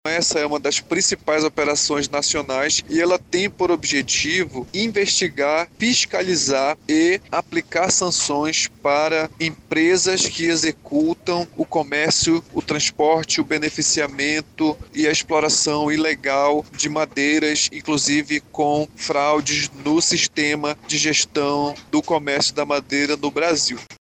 Joel Araújo, superintendente do IBAMA Amazonas, explica que a Operação tem como foco empresas que praticam ou se beneficiam do desmatamento ilegal.